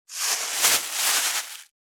605コンビニ袋,ゴミ袋,スーパーの袋,袋,買い出しの音,ゴミ出しの音,袋を運ぶ音,
効果音